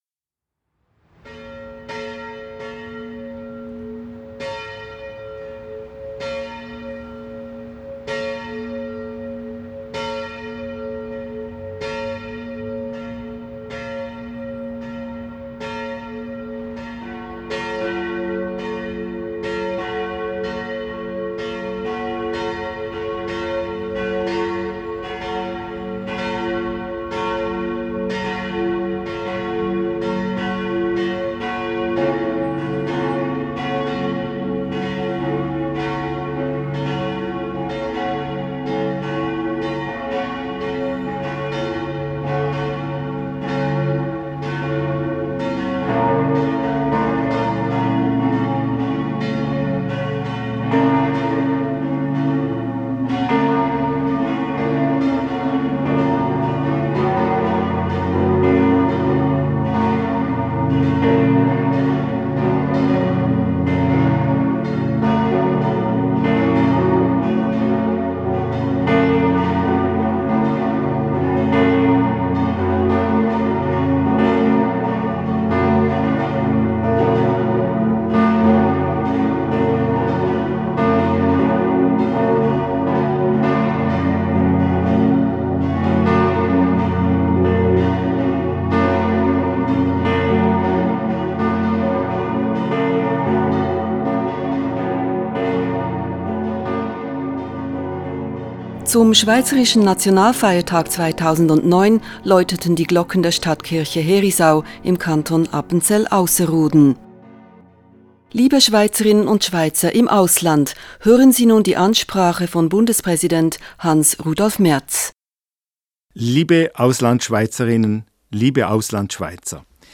Die Rede von Bundespräsident Hans-Rudolf Merz zum Schweizerischen Nationalfeiertag an die Schweizerinnen und Schweizer im Ausland.